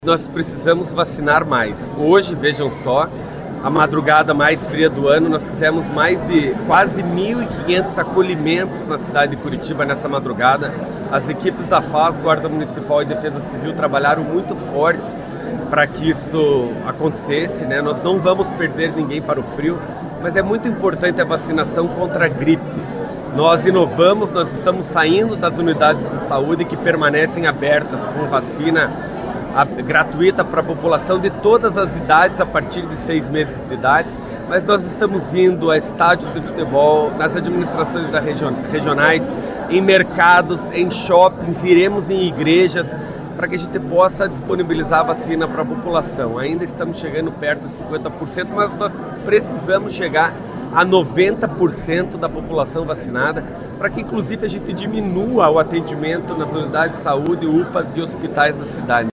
O prefeito de Curitiba, Eduardo Pimentel, destacou, nesta quinta-feira (12), que o índice de vacinação contra a gripe na cidade continua muito abaixo do esperado.
SONORA-PIMENTEL-VACINA-GJ.mp3